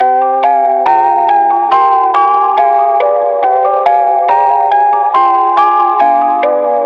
GOLD_140_G.wav